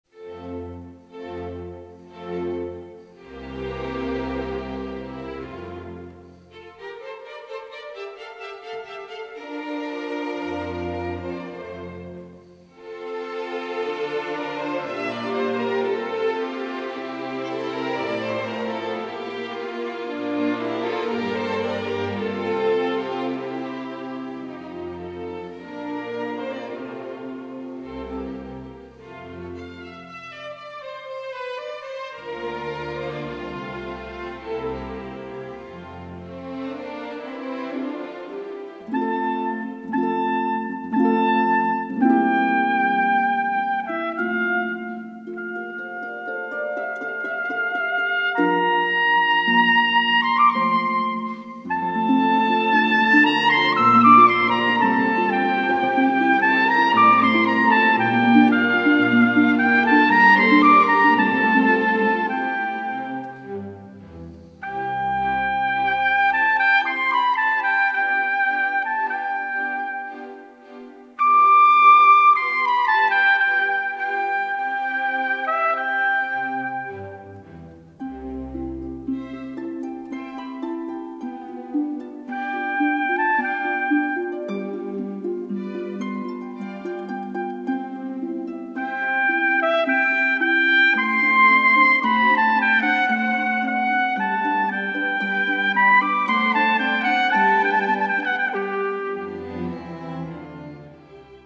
Concerto in C mojor for flute, harp and orchestra